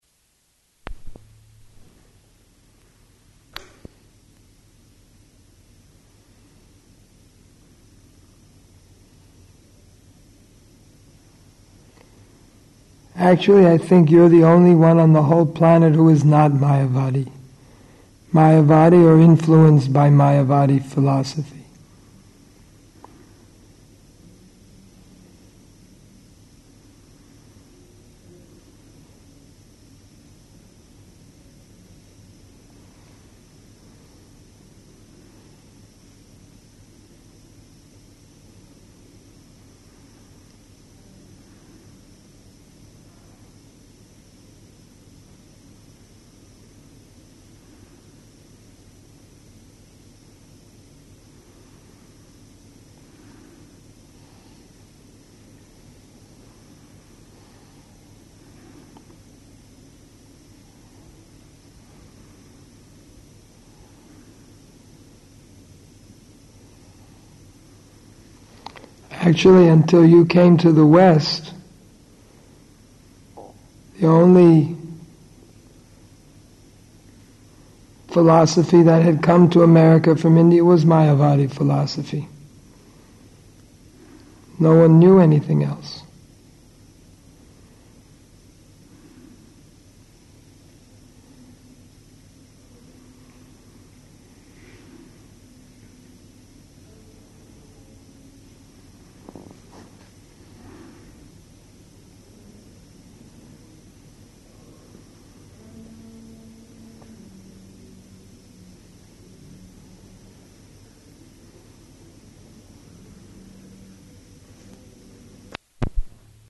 Room Conversation [partially recorded]
Location: Vṛndāvana